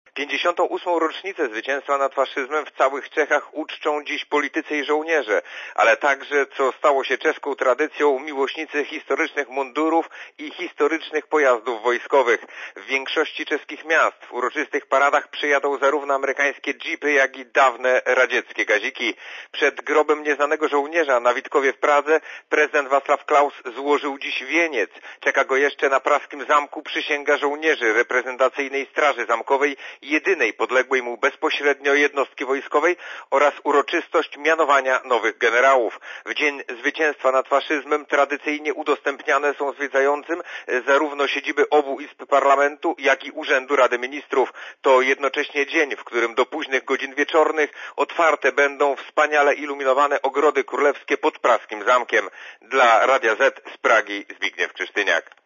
Korespondencja z Pragi (220Kb)